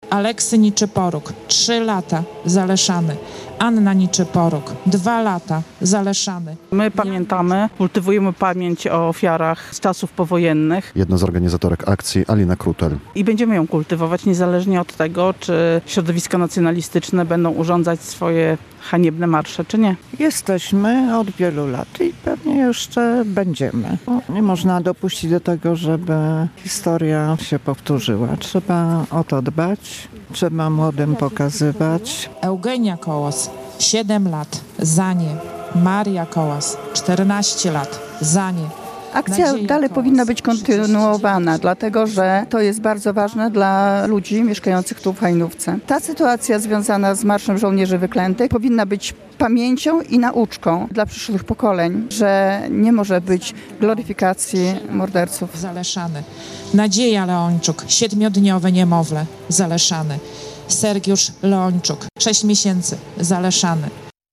Złożeniem kwiatów i wspólną modlitwą upamiętnili ofiary pacyfikacji okolicznych wsi. W centrum Hajnówki mieszkańcy wzięli udział w akcji pod hasłem "Wieczna Pamięć".
relacja